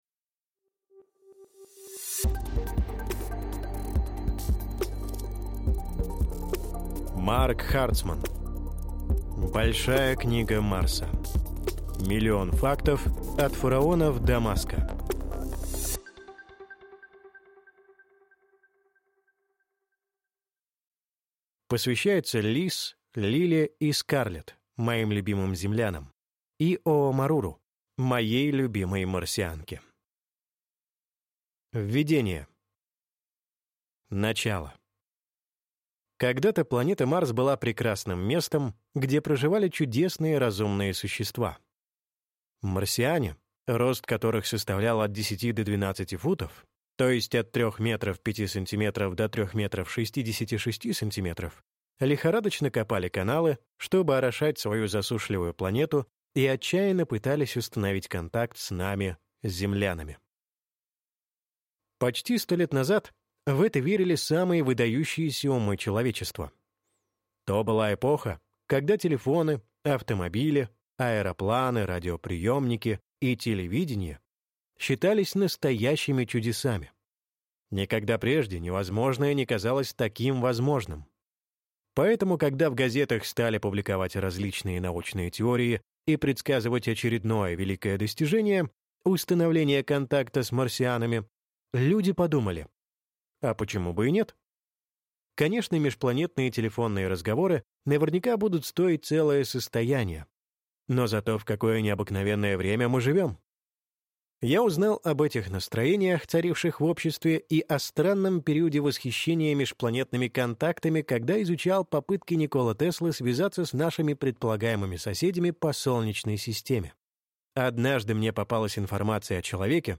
Аудиокнига Большая книга Марса. Миллион фактов от фараонов до Маска | Библиотека аудиокниг